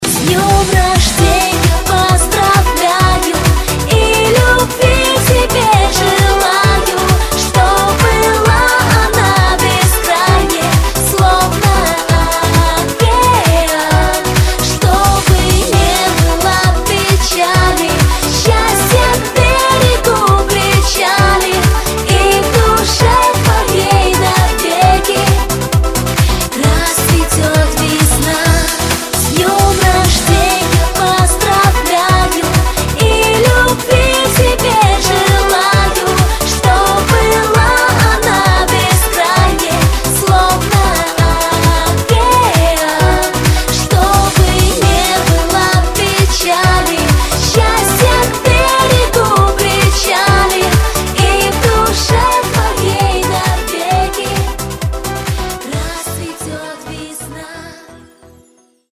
Танцевальные рингтоны , Поп